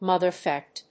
アクセント・音節móther・cràft